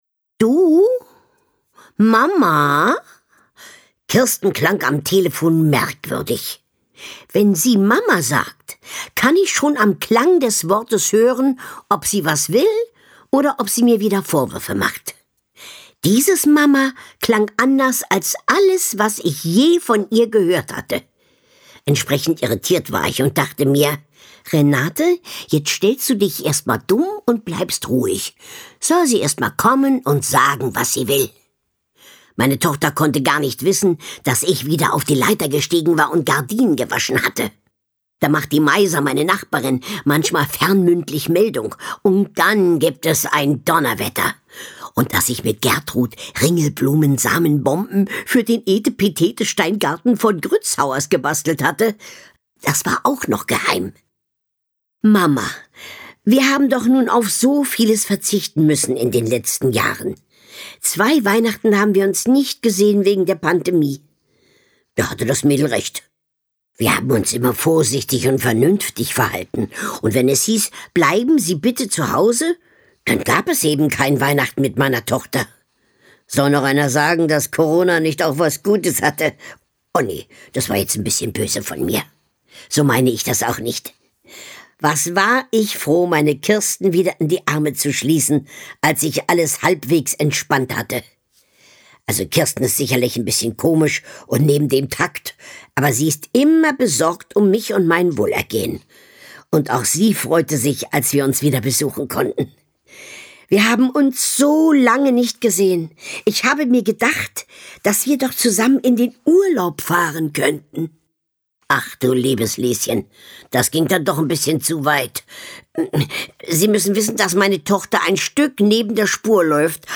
Carmen-Maja Antoni (Sprecher)
Ungekürzte Lesung mit Carmen-Maja Antoni